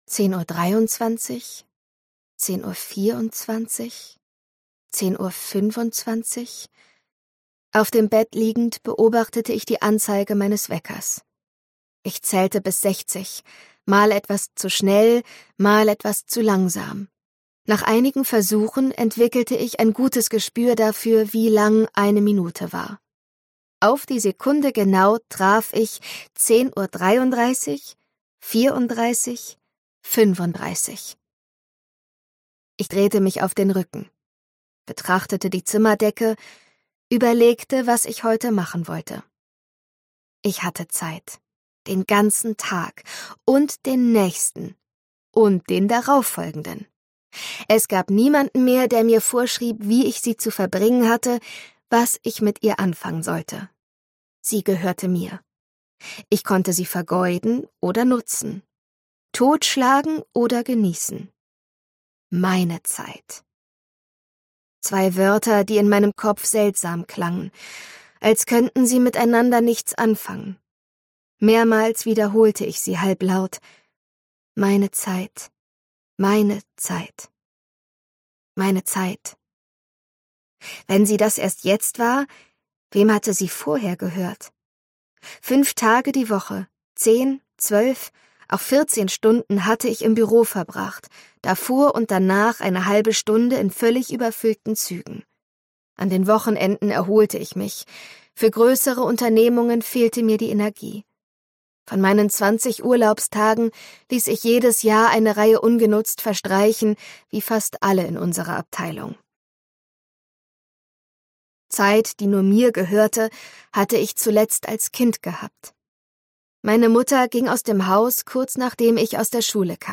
2025 | Ungekürzte Lesung